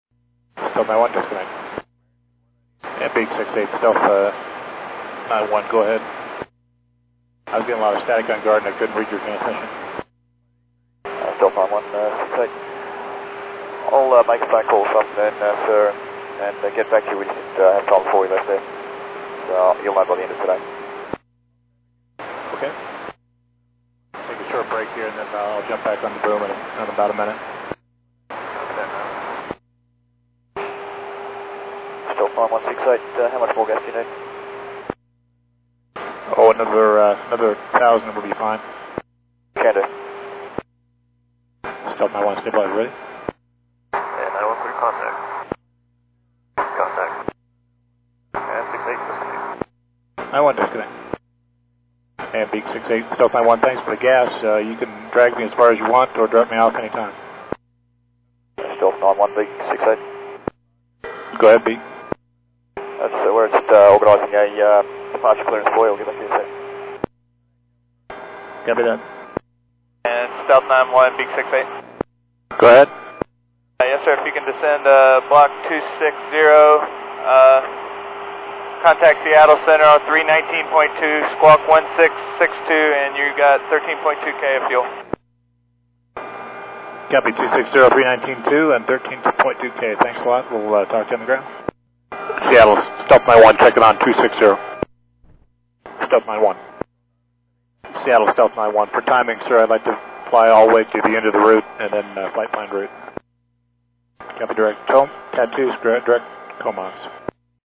Последняя минута радиосвязи шаттла "Columbia" перед разрушением
Начало » Записи » Записи радиопереговоров - лучшие